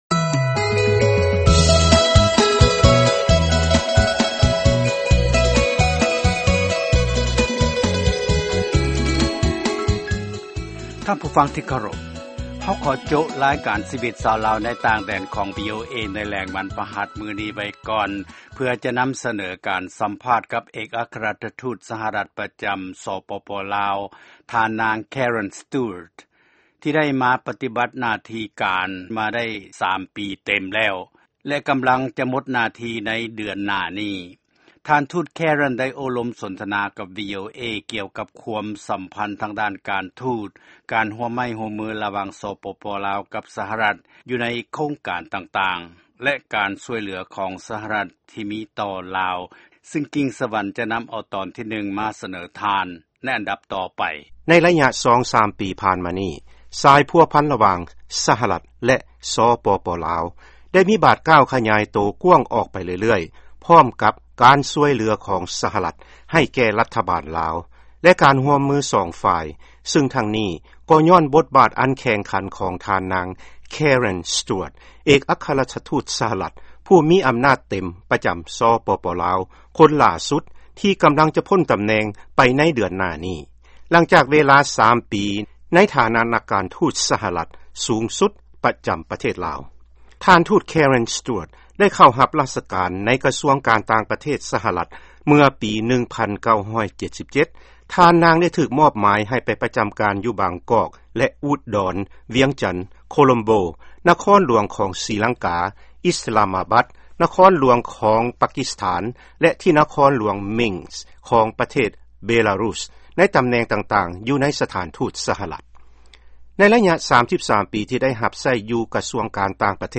ຟັງການສໍາພາດພິເສດ ກັບທ່ານນາງ ແຄເຣັນ ເອກອັກຄະລັດຖະທູດ ສະຫະລັດ ປະຈໍາ ສປປ ລາວ